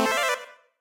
Techmino/media/SFX/spawn_3.ogg at eac6800a171e8d8defbc35e36b2e784365a0d56f
spawn_3.ogg